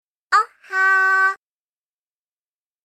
📌 Energetic & Trendy → A fun and trendy way to say “Good morning.” Originally popularized by Japanese TV personalities, this version gives off a lively vibe.